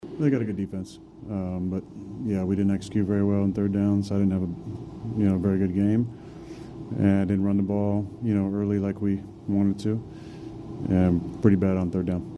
Quarterback Aaron Rodgers said that the Steelers were not good enough on third down.